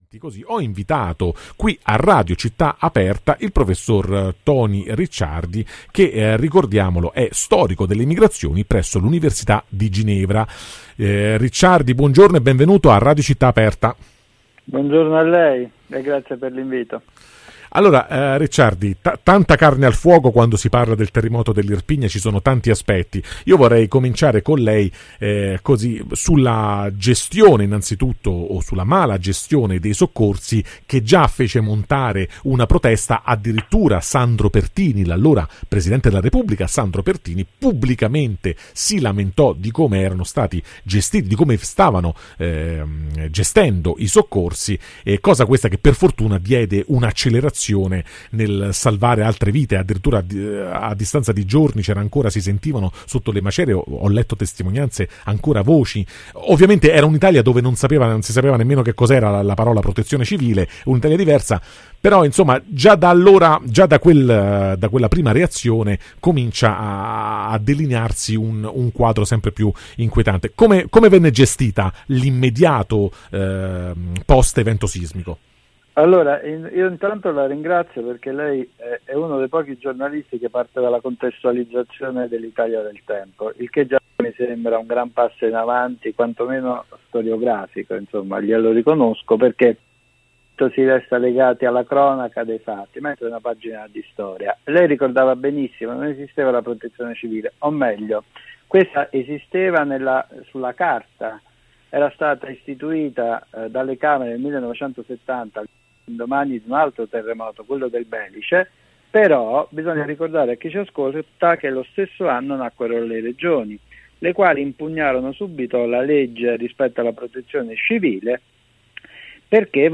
Il terremoto dell'Irpinia e la fine della Prima Repubblica [intervista al Prof. Toni Ricciardi]